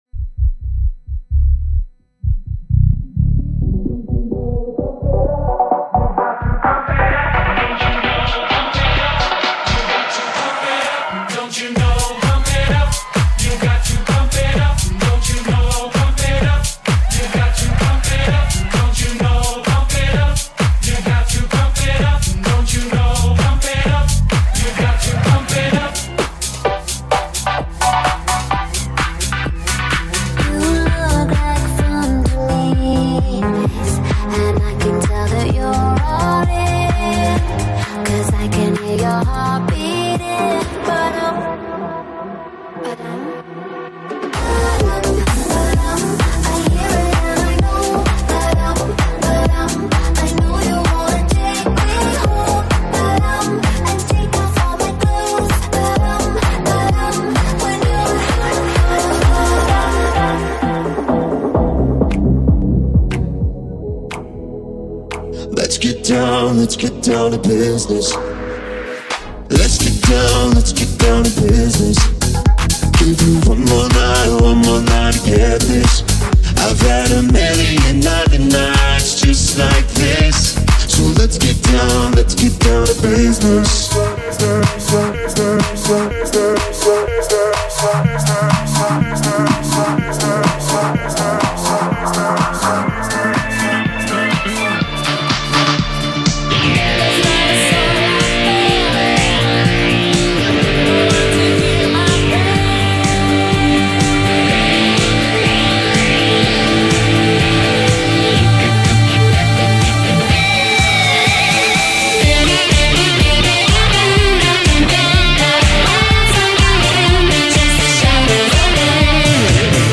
DJ | MC
Open format, can play any genres and style.